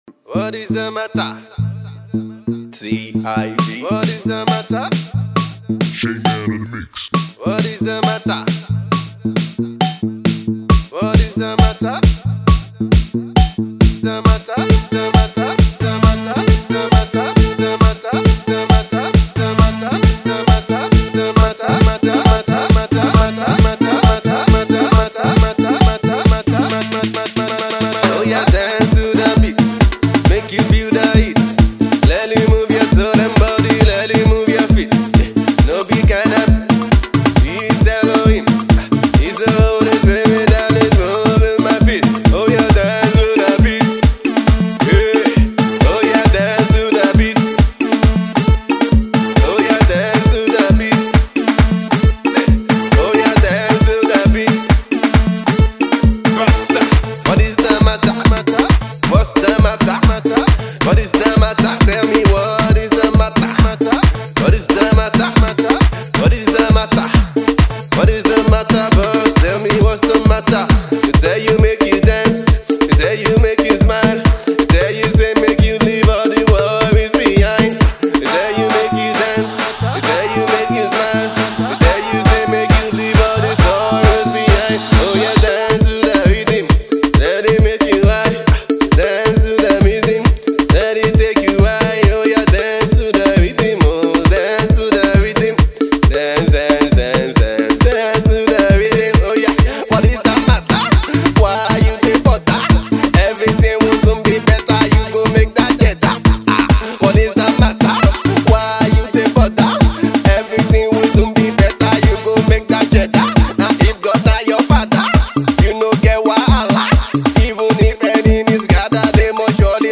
Gospel group